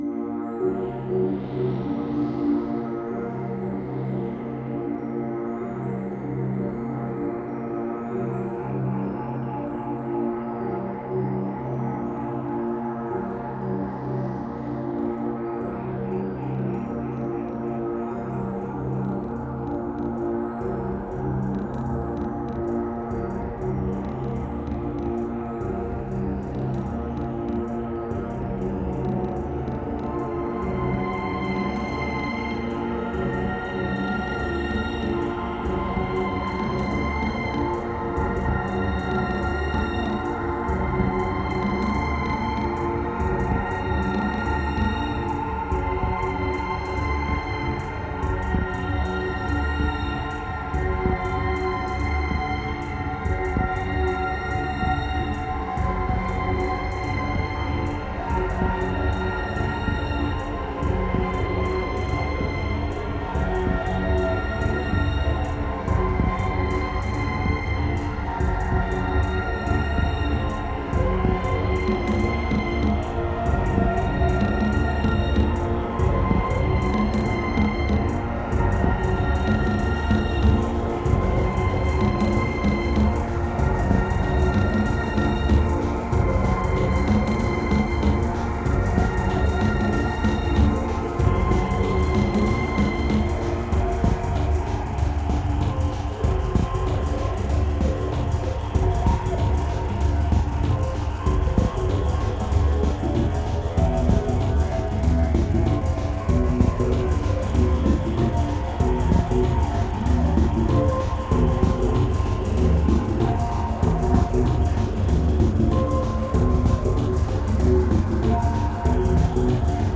Pure remote desire, strong medication and raw electronica.